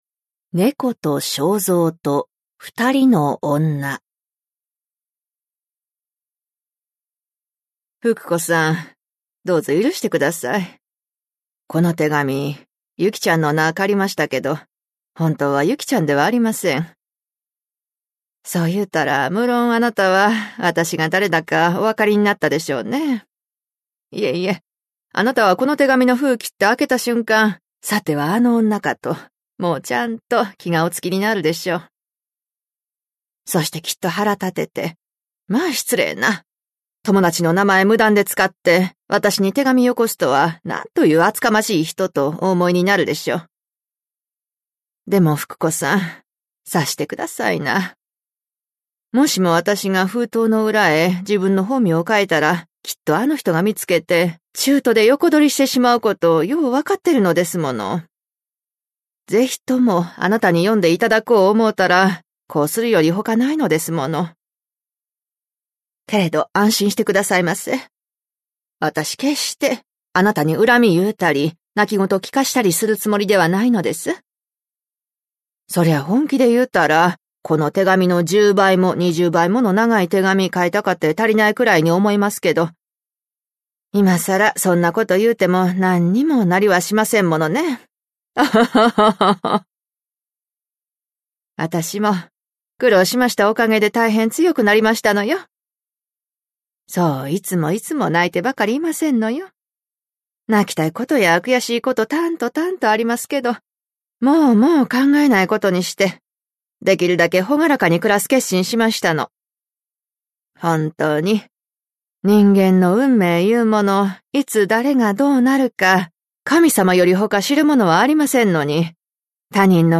[オーディオブック] 谷崎潤一郎「猫と庄造と二人のをんな」